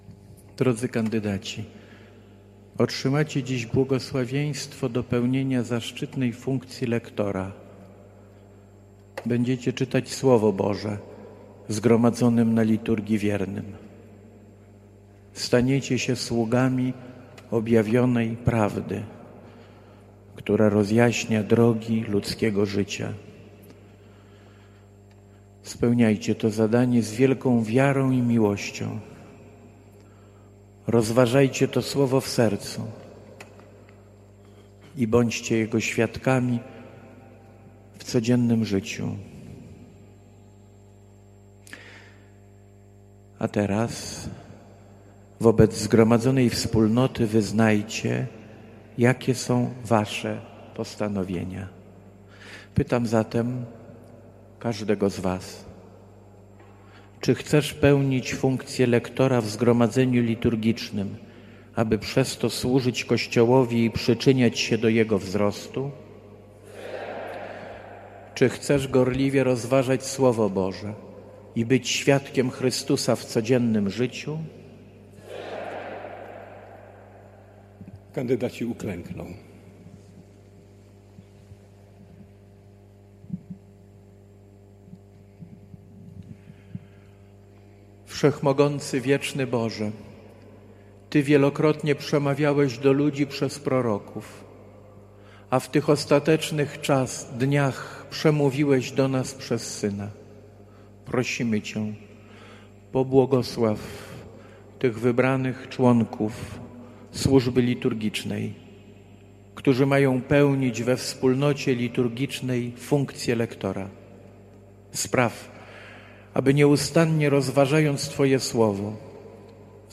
biskup MichałPo kazaniu odbył się obrzęd błogosławieństwa nowych lektorów.
promocja lektorówBiskup Michał przyjął zbiorową deklarację od wszystkich kandydatów i udzielił im zbiorowego błogosławieństwa, modląc się nad całą grupą.
Obrzed-blogoslawienstwa-nowych-lektorow.mp3